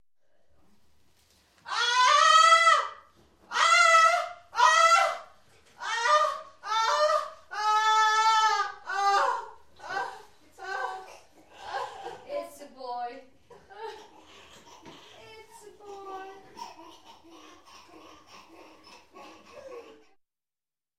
Звуки родовой боли при появлении ребенка на свет